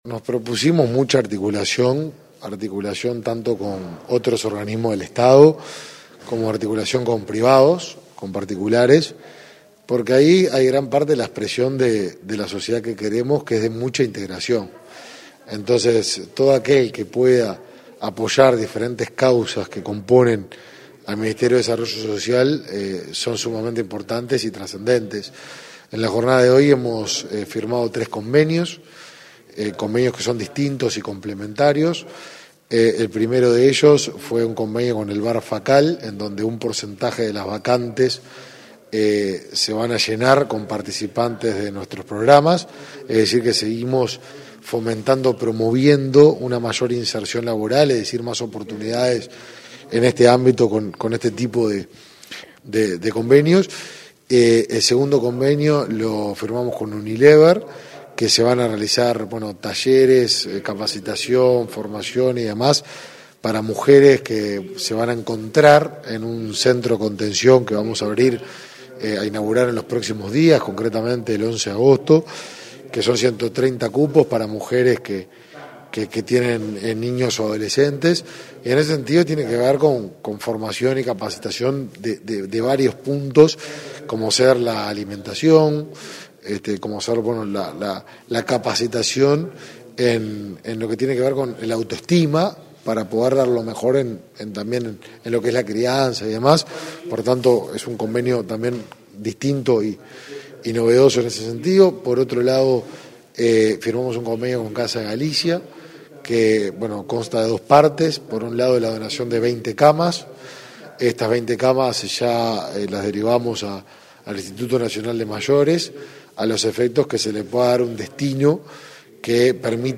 Entrevista al ministro de Desarrollo Social, Martín Lema, sobre convenios con privados